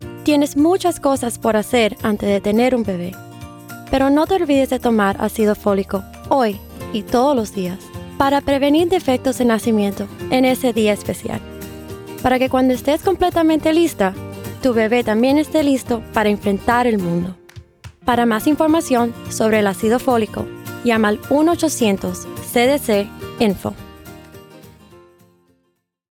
Folic-Acid-PSA_Ready-Not_Spanish.wav